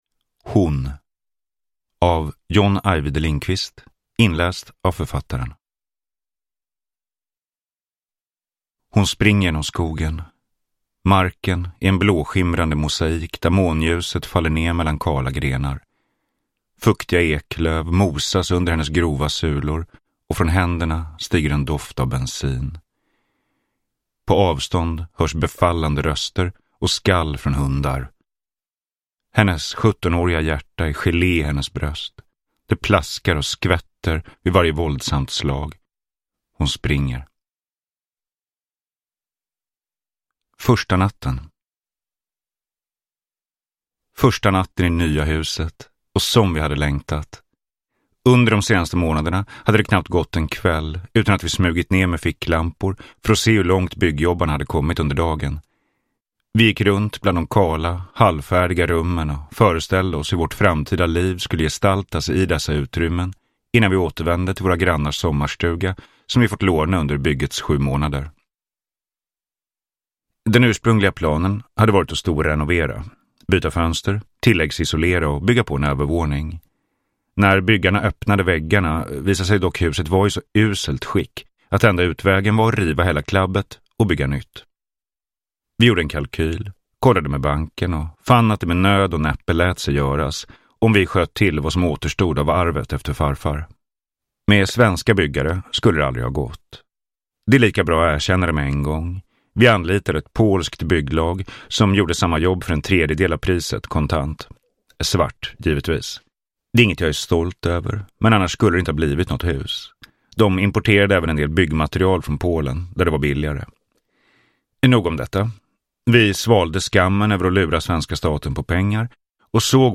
Hon – Ljudbok – Laddas ner
Hon är en nyskriven spökhistoria av John Ajvide Lindqvist, direkt utgiven i ljud och inläst av författaren.
Uppläsare: John Ajvide Lindqvist